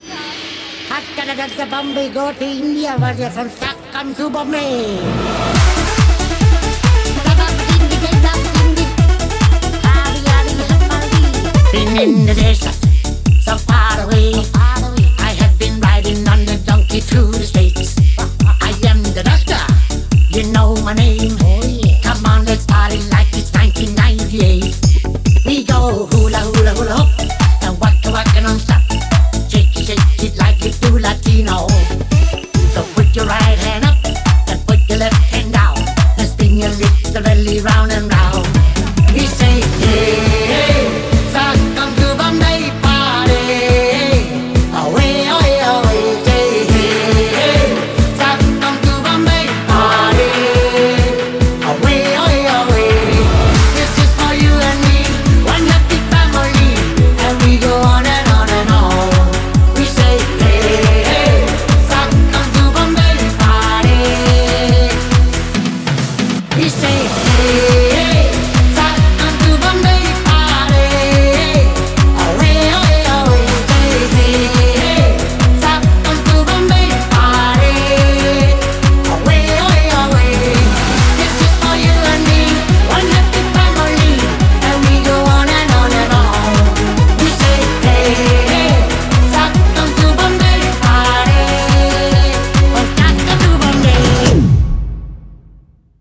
BPM140
Audio QualityPerfect (High Quality)
Commentaires[INDIE EURO/DANCE]